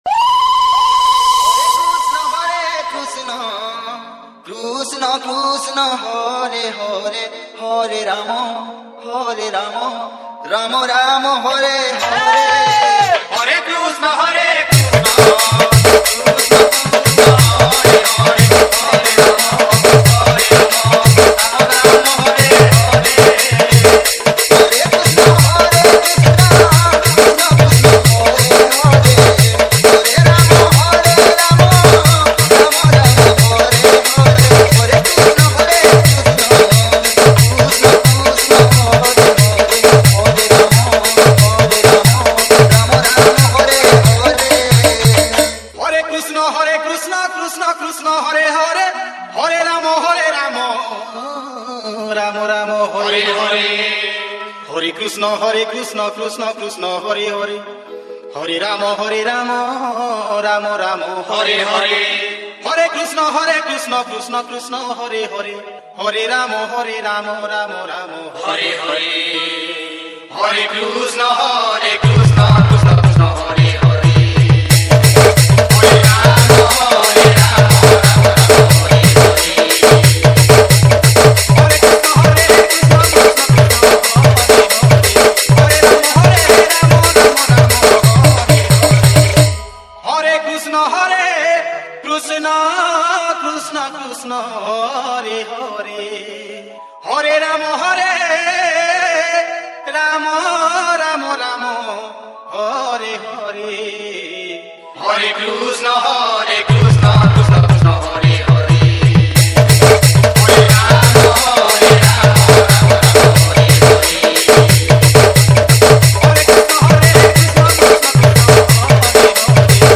SAMBALPURI BHAJAN DJ REMIX